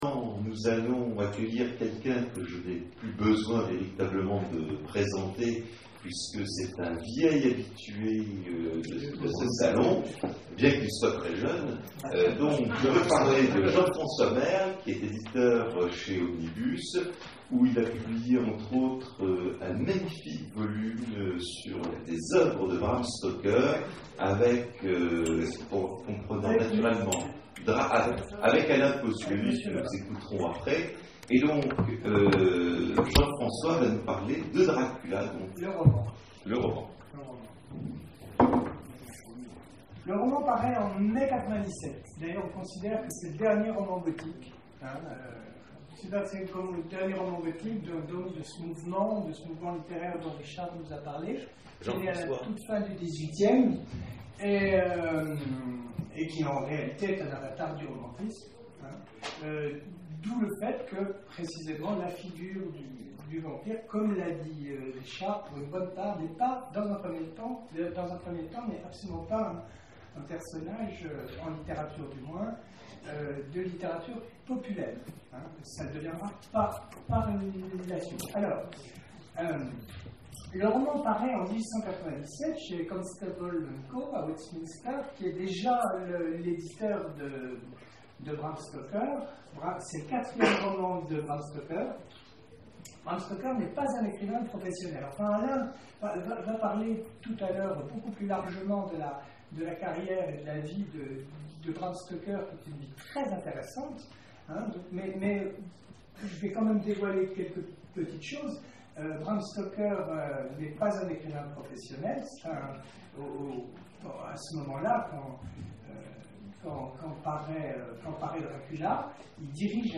Salon du roman populaire d'Elven : conférence sur Dracula
Conférence